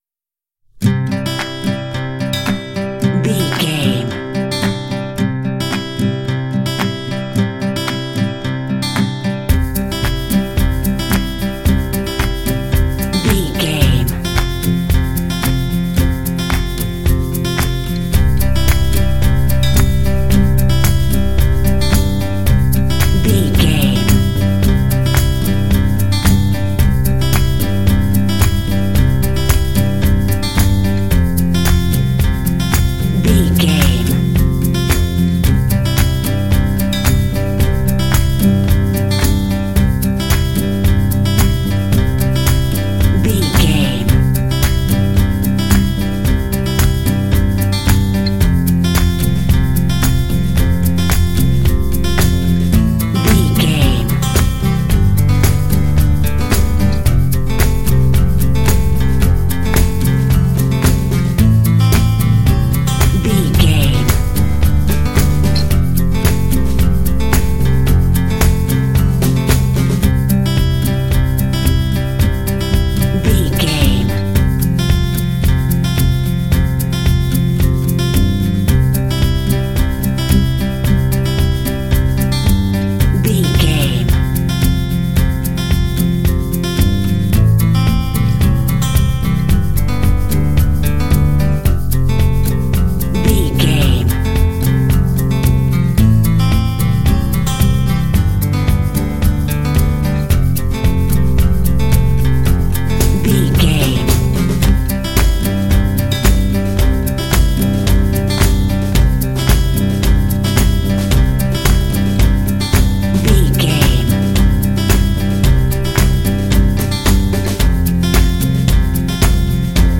Uplifting
Ionian/Major
joyful
acoustic guitar
bass guitar
drums
percussion
indie
pop
contemporary underscore